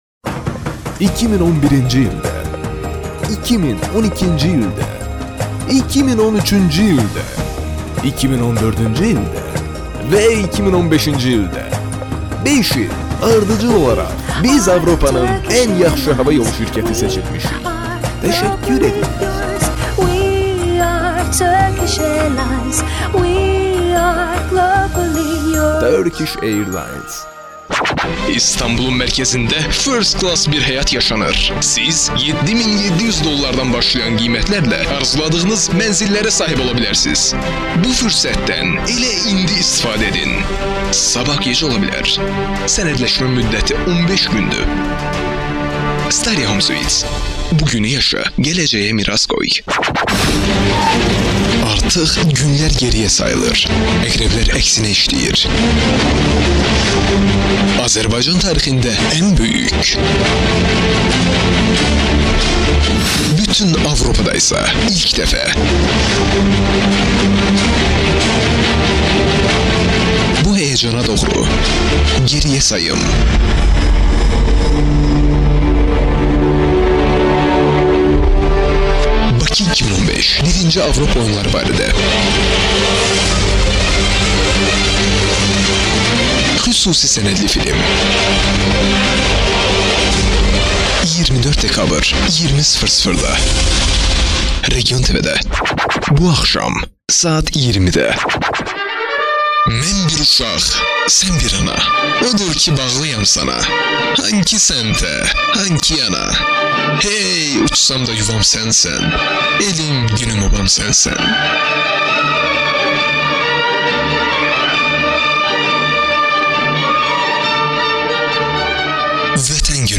Azerice Seslendirme